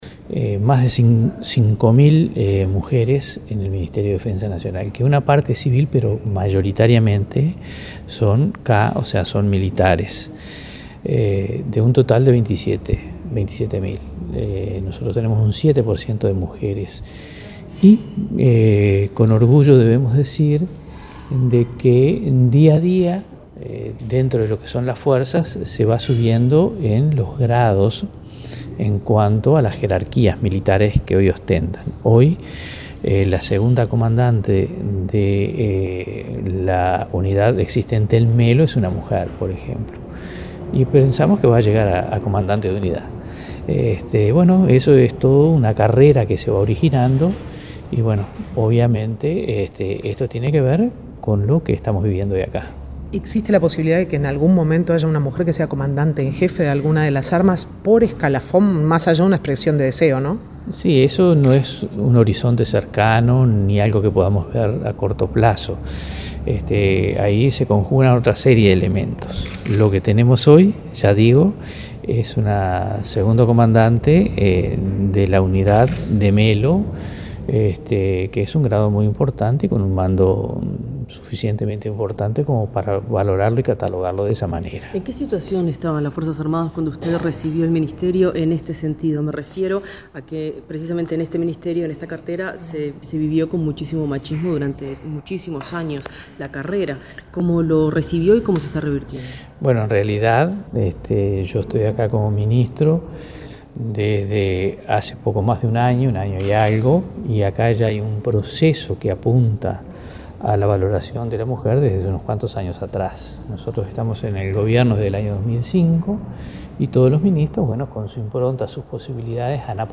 En el Ministerio de Defensa, más de 5.000 mujeres desempeñan funciones en diferentes áreas. “Hay un proceso que apunta a la valoración de la mujer, que comenzamos en 2005. Buscamos reivindicar a la mujer con derechos y deberes y aportamos a la nueva masculinidad”, afirmó el ministro de Defensa Nacional, Jorge Menéndez, al finalizar la primera reunión del Consejo Nacional de Género.